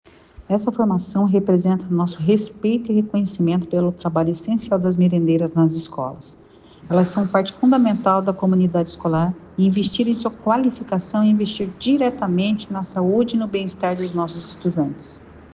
Sonora da diretora-presidente do Fundepar, Eliane Teruel Carmona, sobre a capacitação de 2 mil merendeiras pelo Estado